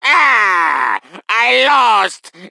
WarioMP4_-_AAAHHH_I_LAWST.oga